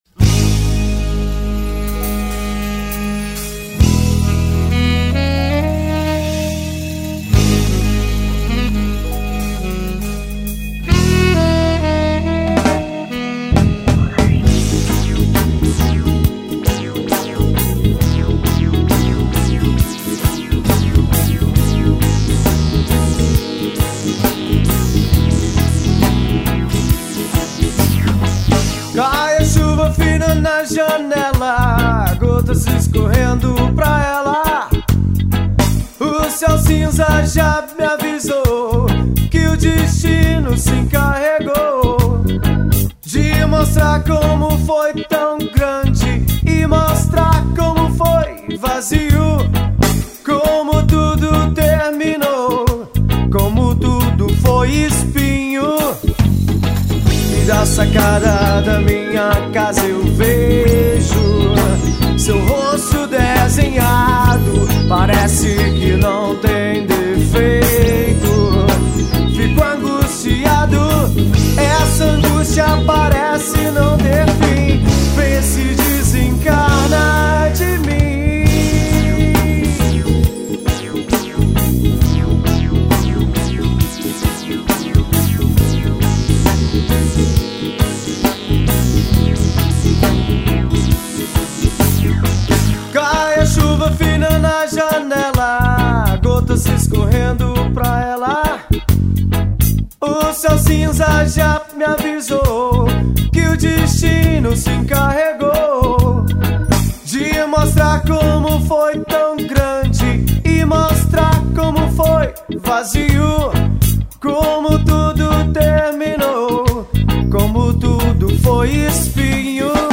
1969   03:48:00   Faixa:     Reggae